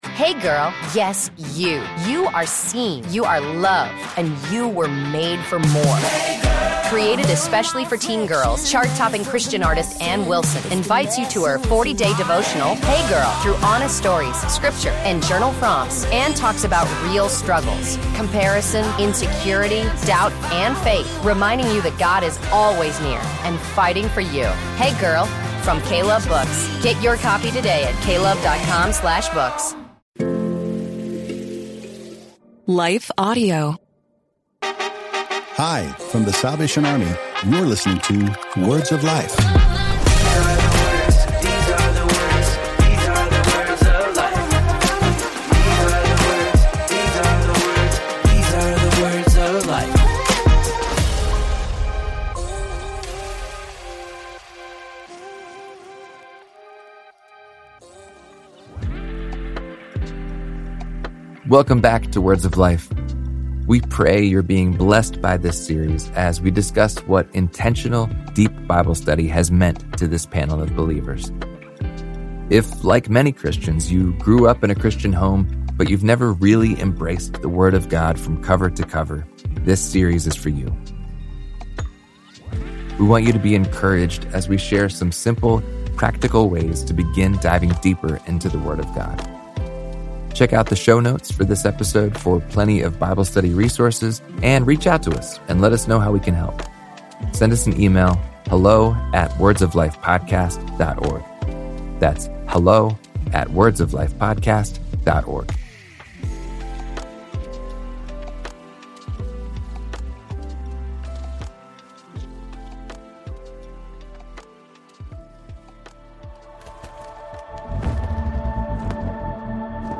In this powerful episode of Words of Life, our panel dives deep into one of the most difficult and frequently asked questions in Christian theology: Why do bad things happen to good people? Drawing from both Scripture and lived experience, our guests explore how God can bring redemption, endurance, and even unexpected beauty out of seasons of pain.